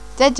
(popular canario)